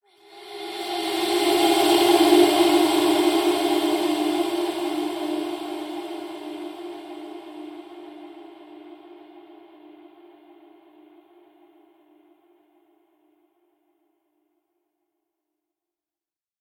Поющие призраки